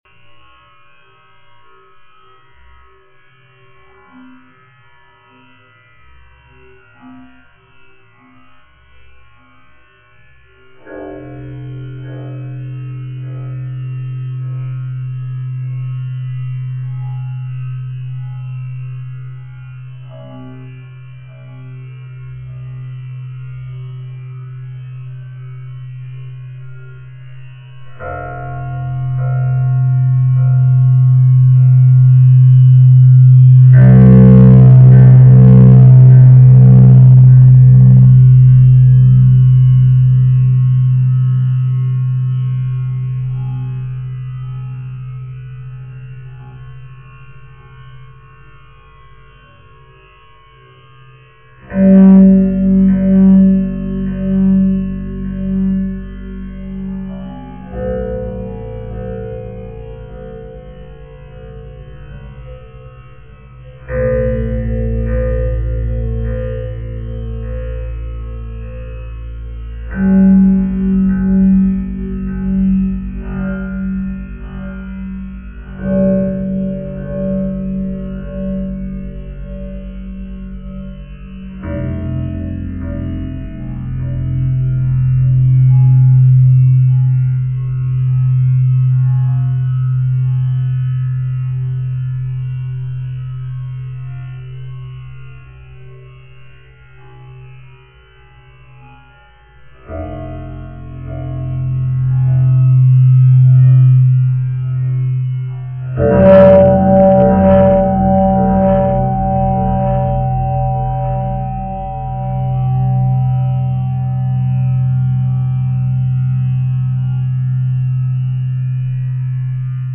Hören könnte man wenn man es auf sich nähen würde diese 9.6 Megabyte an Dateiemenge herunterzuladen 12 Minuten oder noch mehr allerbester 7-Tonaler Musik auf einer geschwindigkeitsbeschränkten Kanteele einverspielt.